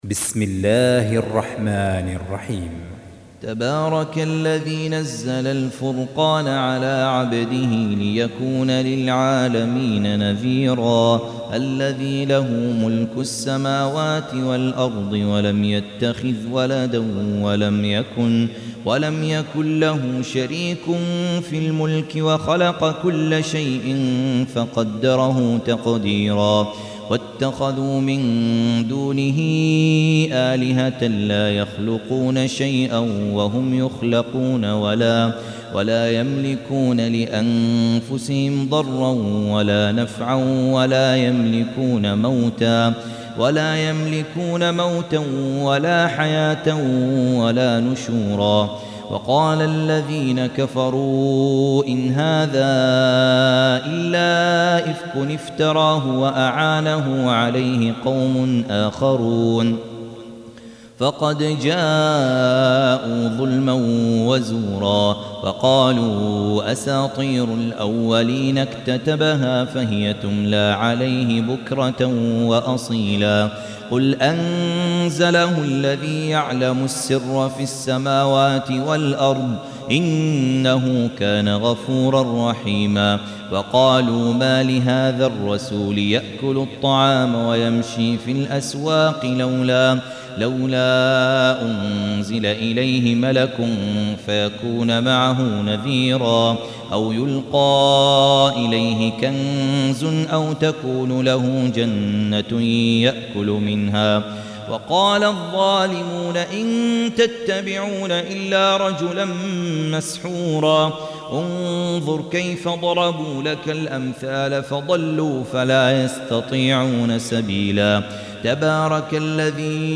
Surah Repeating تكرار السورة Download Surah حمّل السورة Reciting Murattalah Audio for 25. Surah Al-Furq�n سورة الفرقان N.B *Surah Includes Al-Basmalah Reciters Sequents تتابع التلاوات Reciters Repeats تكرار التلاوات